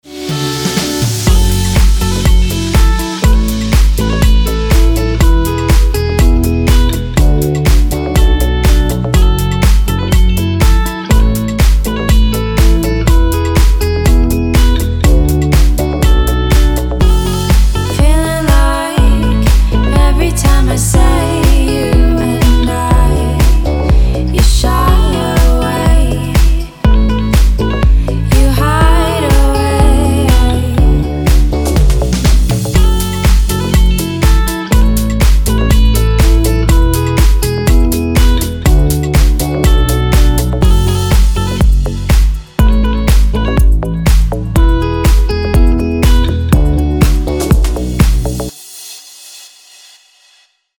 • Качество: 320, Stereo
гитара
красивые
deep house
приятные
теплые
акустическая гитара
Ну очень теплый deep house)))